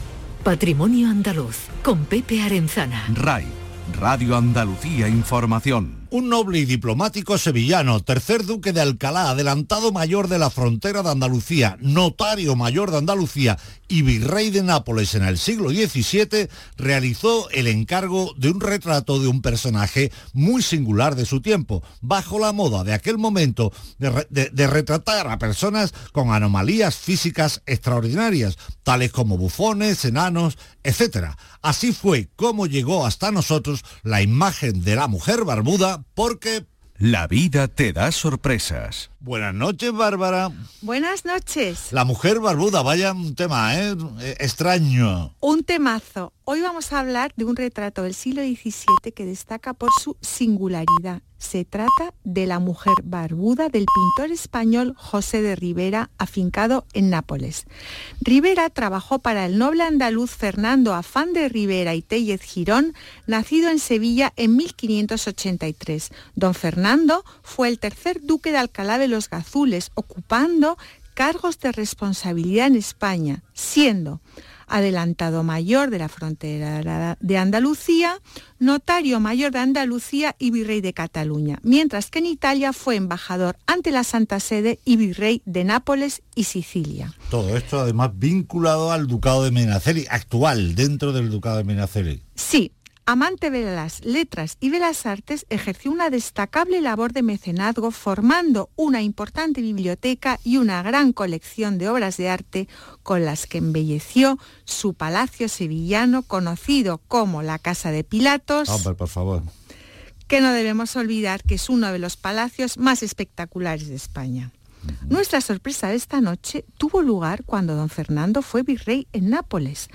Aquí os dejo mi intervención en el programa de Radio Andalucía Información, «Patrimonio andaluz» del día 18/12/2022